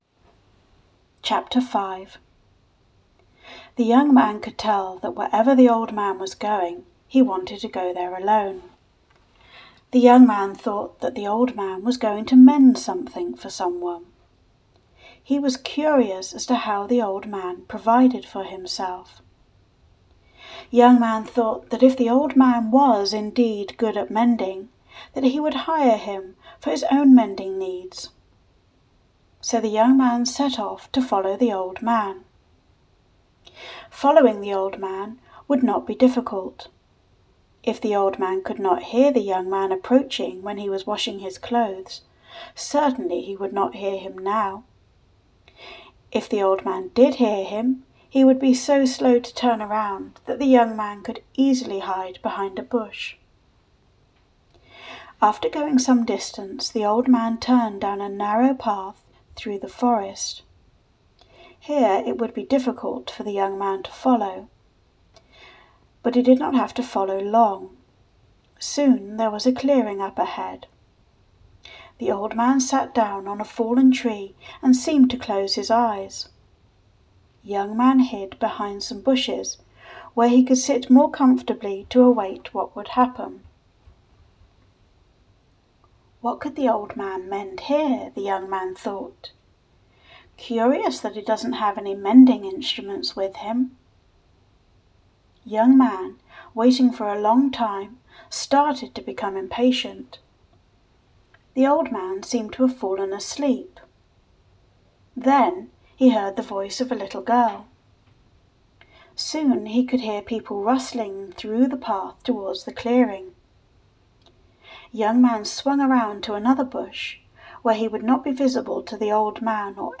The Young Man and the Old Man - Audiobook